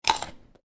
11. Звук кусания моркови